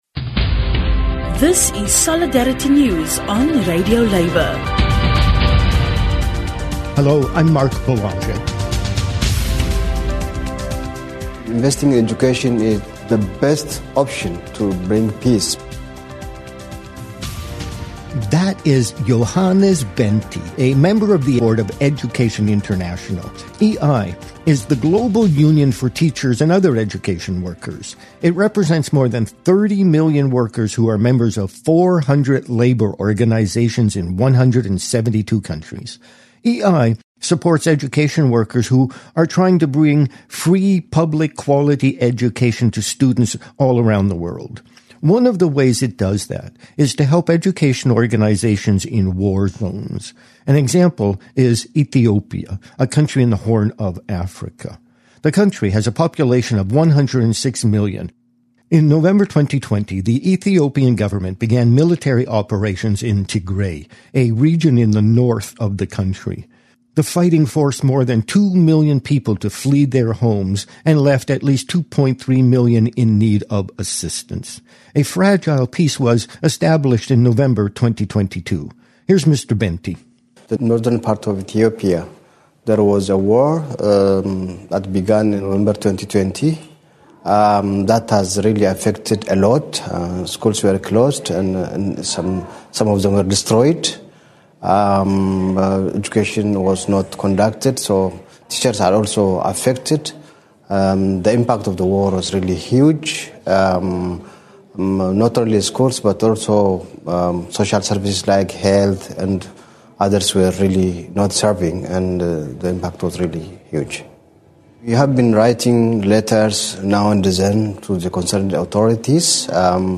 International Labour News